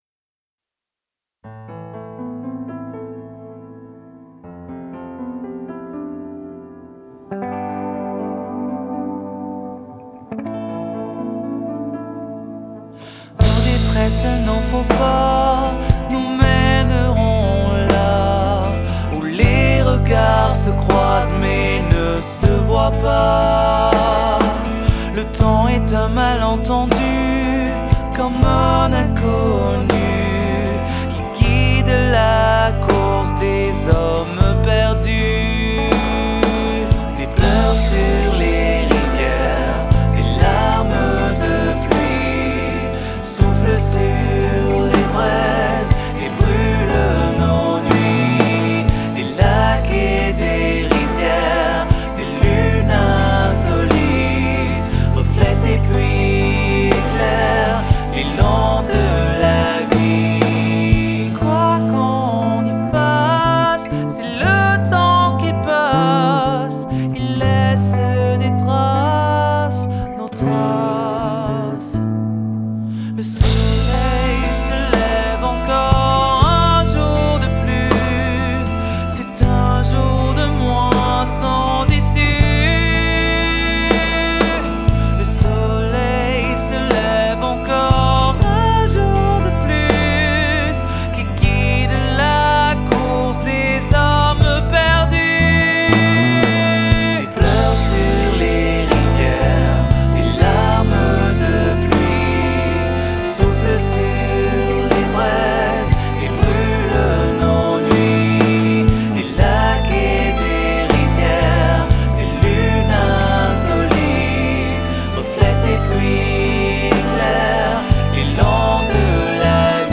Live Bergerac 2016